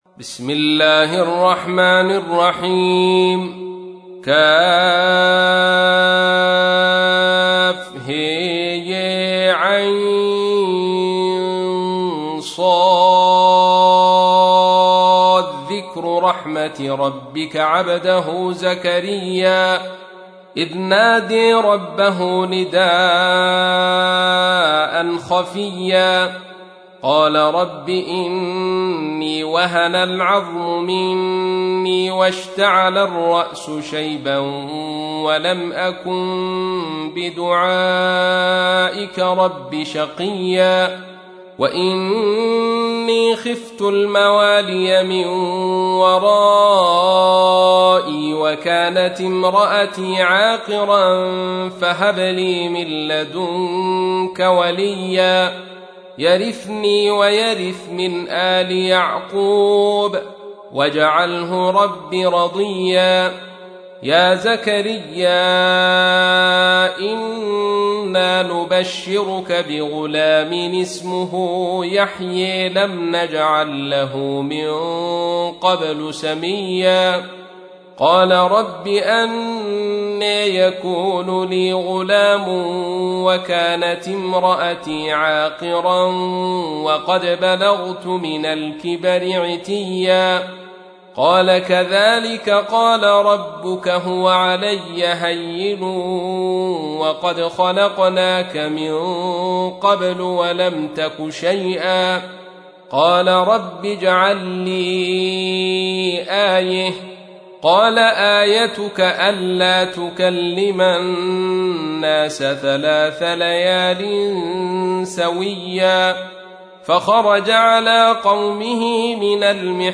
تحميل : 19. سورة مريم / القارئ عبد الرشيد صوفي / القرآن الكريم / موقع يا حسين